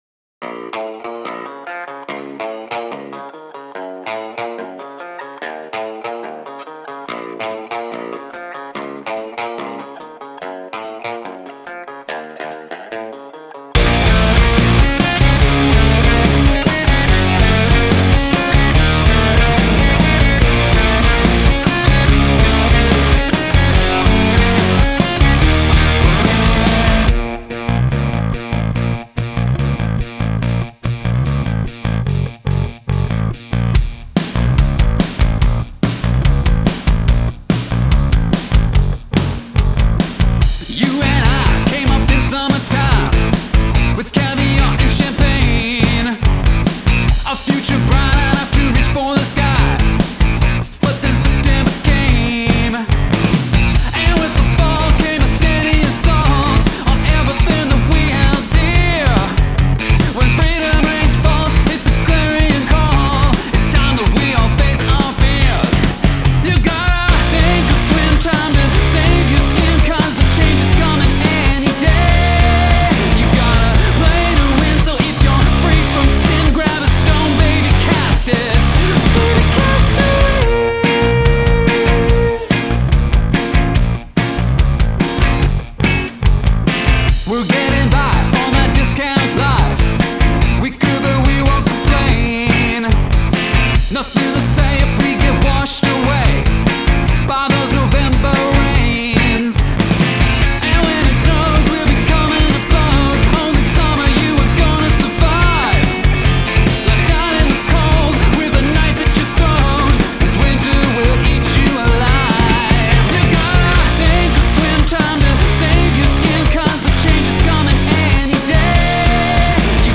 Catchy intro.
This is the short lyrics w/ strong melody I'm looking for.